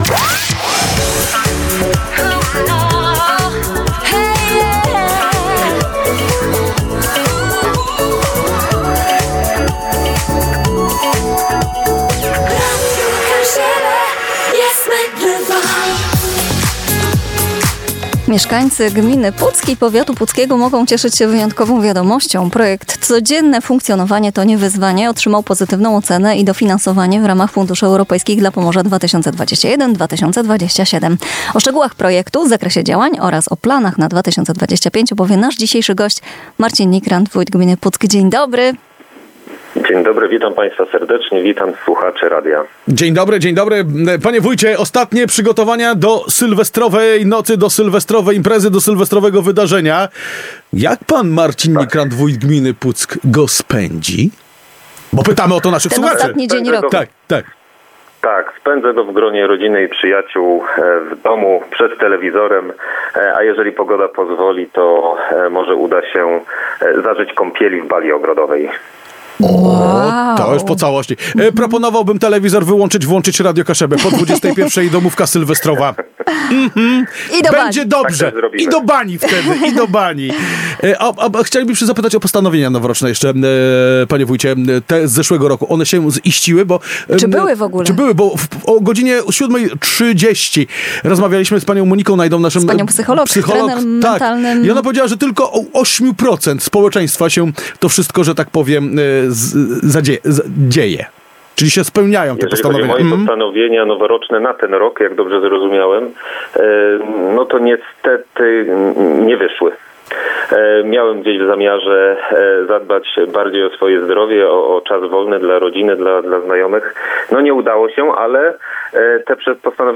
rozmowa_mNikrant.mp3